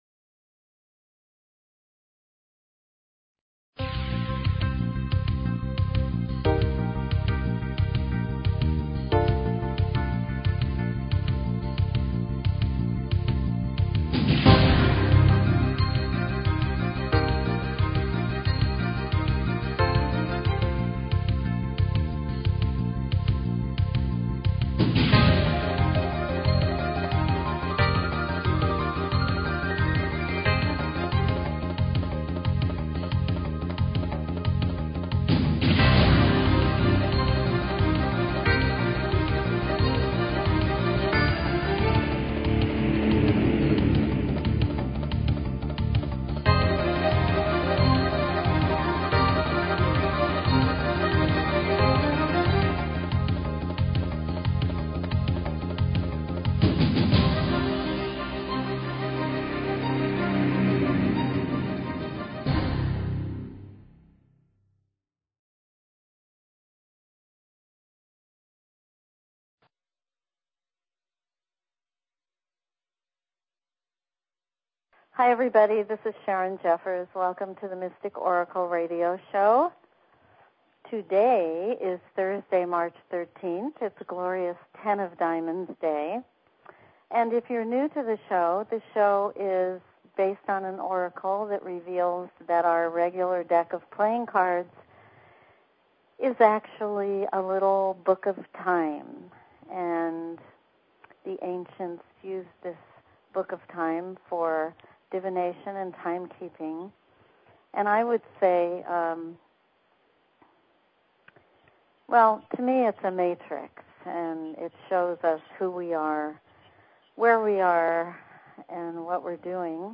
Courtesy of BBS Radio
Open lines for calls.